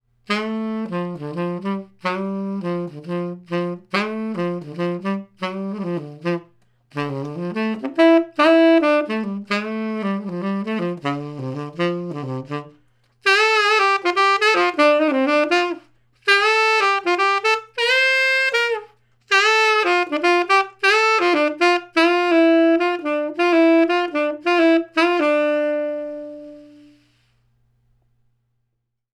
Four couples of microphones have been placed in front of some instruments, in the same position and at the same time, to compare the results for stereo tracking.
Coincident cardiods
2 x 1/2″ diaphraghm condenser,coincident,130 degrees axes.
Coincident- Sax
Coincident_Sax.wav